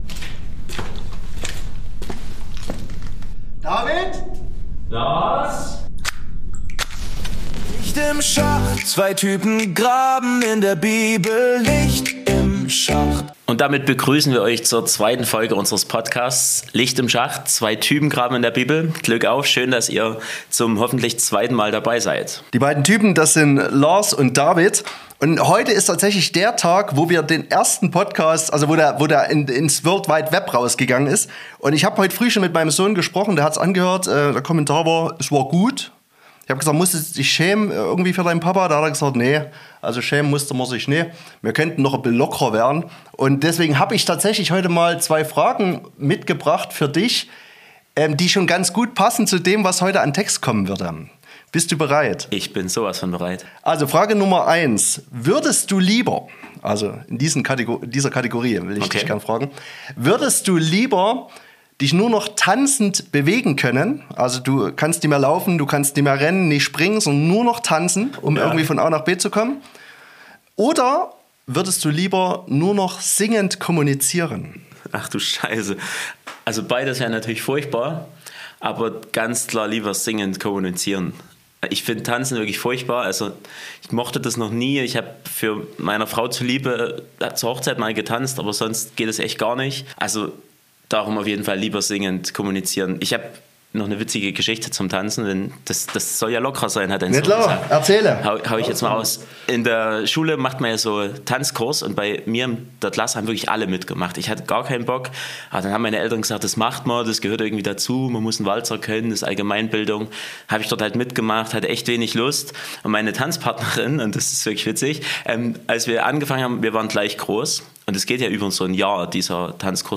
Wir werfen natürlich auch wieder einen Blick in unseren Bibelleseplan: Den Lobgesang des Zacharias (Lukas 1, 67-80). Zwei alte weiße Männer reden über den feministischen Zugang zu diesem Text.